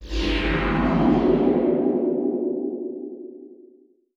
8 bits Elements
Magic Demo